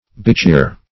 Search Result for " bichir" : The Collaborative International Dictionary of English v.0.48: Bichir \Bi*chir"\, n. [Native name.]